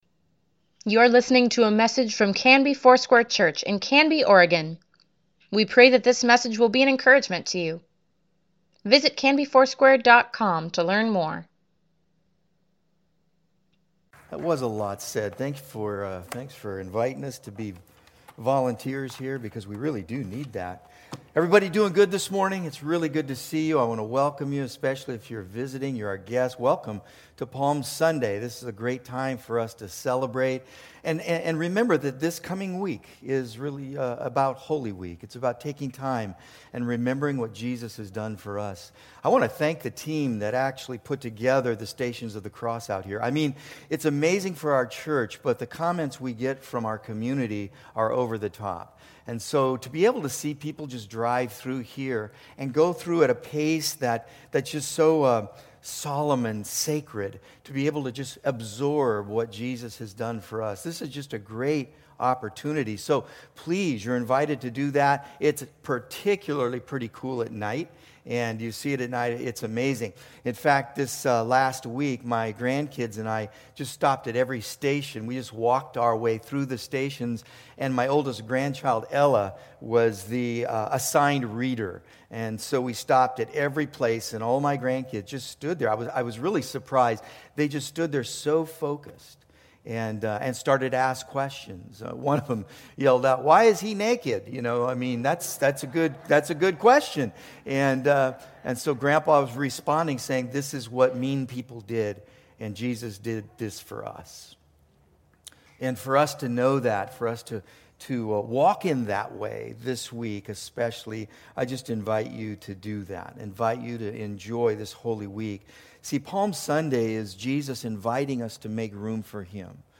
Weekly Email Water Baptism Prayer Events Sermons Give Care for Carus Making Room - Palm Sunday March 28, 2021